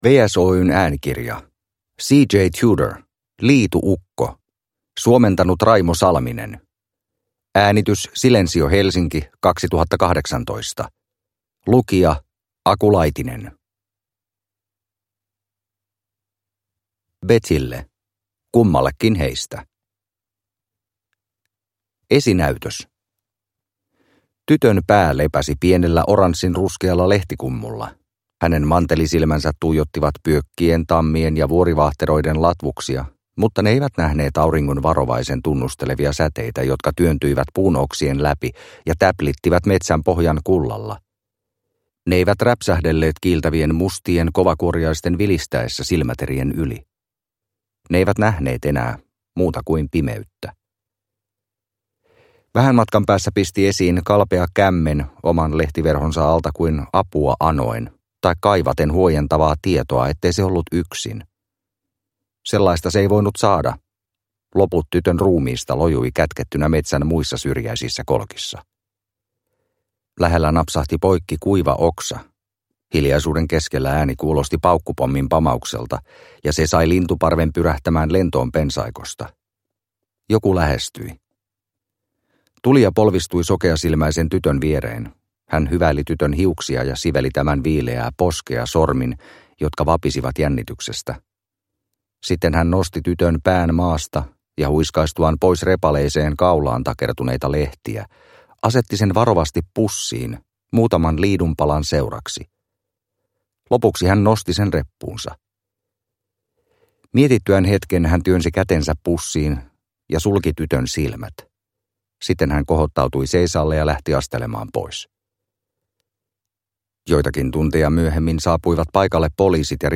Liitu-ukko – Ljudbok – Laddas ner